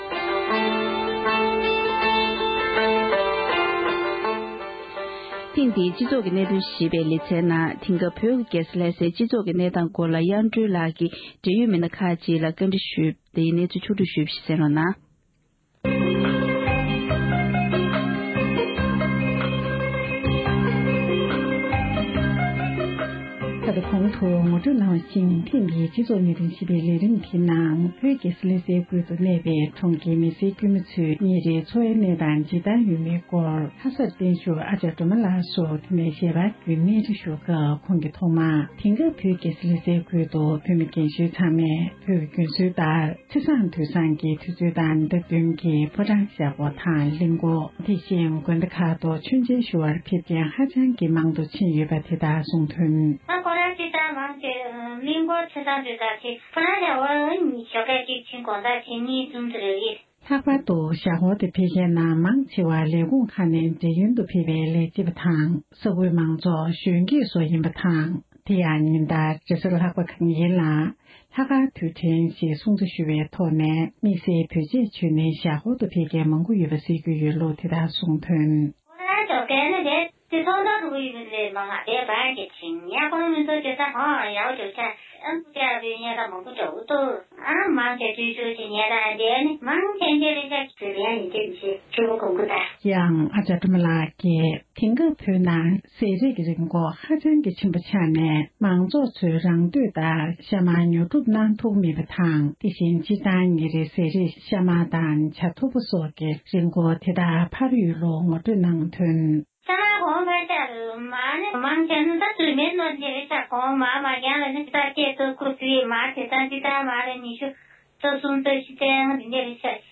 དེ་སྐབས་བོད་ཀྱི་རྒྱལ་ས་ལྷའི་སྤྱི་ཚོགས་ཀྱི་གནང་སྟངས་སྐོར་འབྲེལ་ཡོད་མི་སྣ་ཁག་ལ་བཀའ་འདྲི་ཞུས་པ།